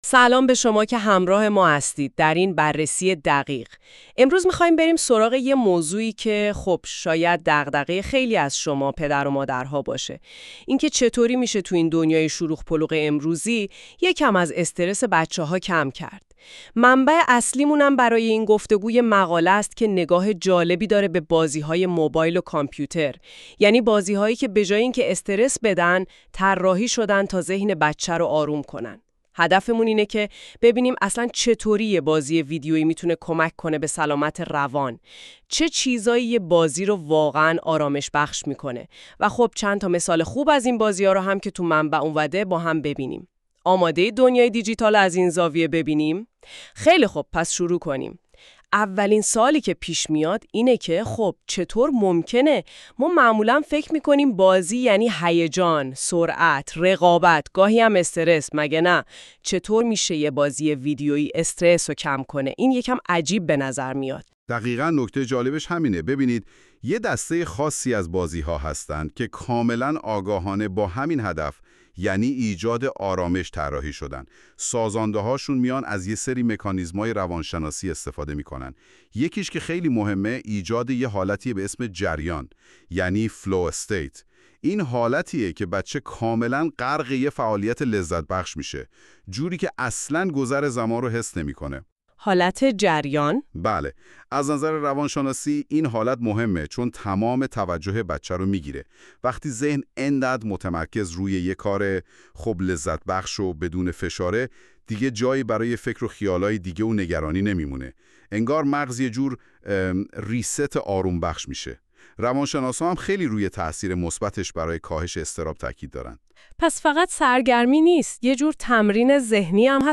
این خلاصه صوتی به صورت پادکست و توسط هوش مصنوعی تولید شده است.